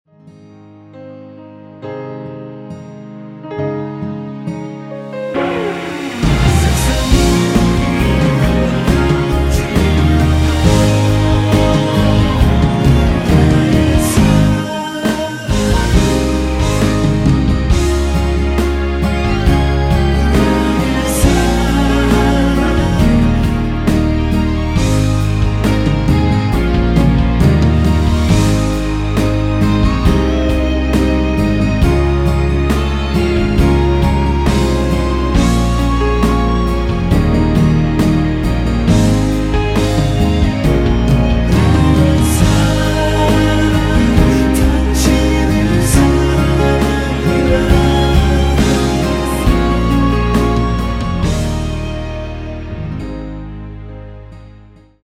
(-1) 내린코러스 포함된 MR 입니다.(미리듣기 참조)
Ab
◈ 곡명 옆 (-1)은 반음 내림, (+1)은 반음 올림 입니다.